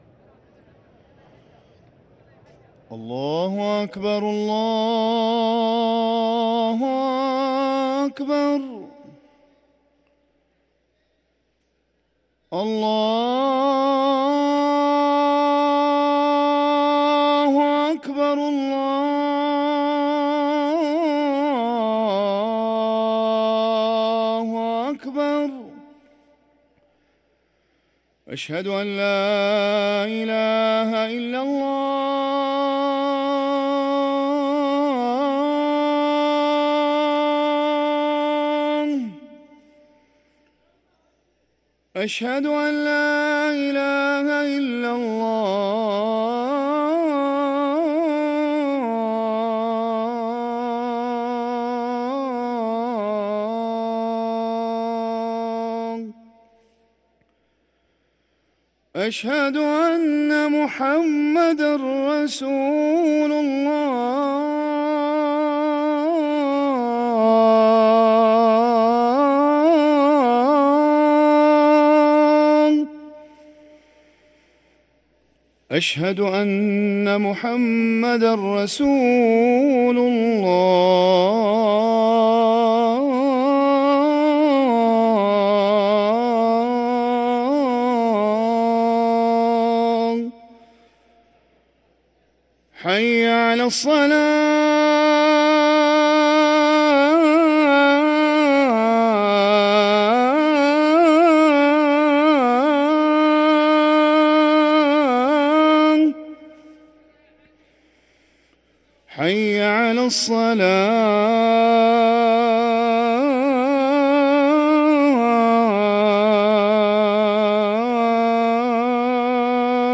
أذان المغرب
ركن الأذان